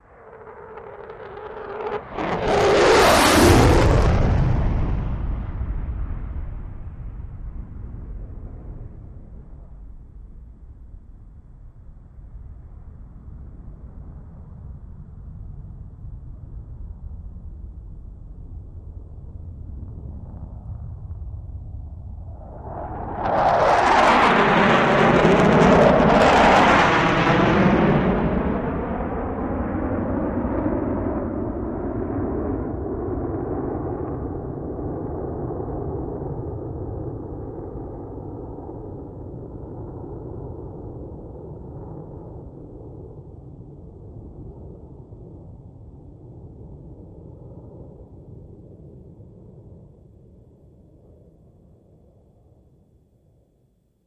F-16 Fighting Falcon
F-16 By Turn By